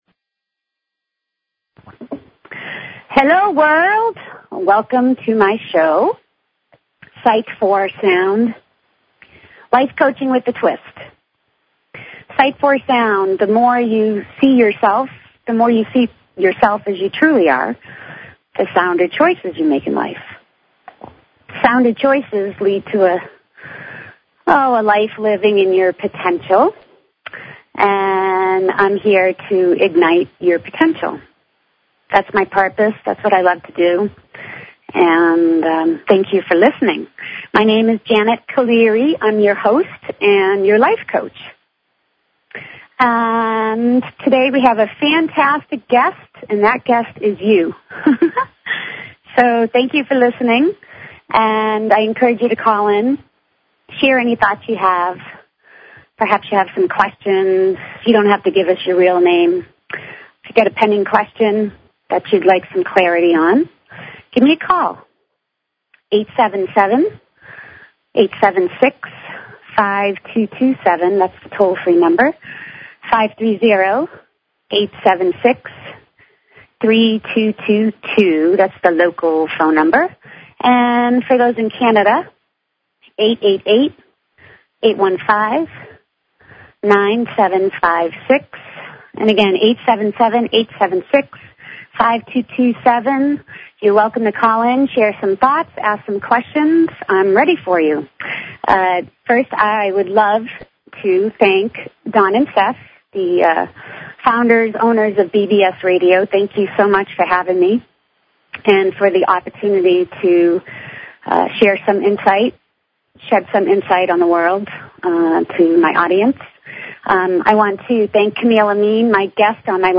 Talk Show Episode, Audio Podcast, Sight_for_Sound and Courtesy of BBS Radio on , show guests , about , categorized as
She encourages you to call in and ask questions or share thoughts!!!